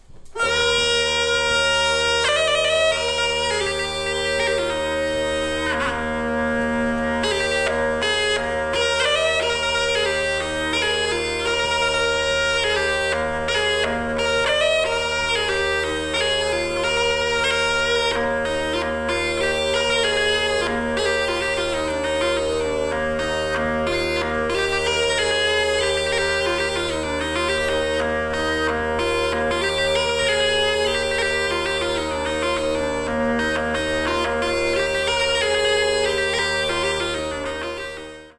Wielkopolska – kozioł biały - Muzeum Ludowych Instrumentów Muzycznych w Szydłowcu
Wielkopolska - kapela z kozłem białym (weselnym), skrzypcami i klarnetem w stroju es
koziol-weselny-bialy.mp3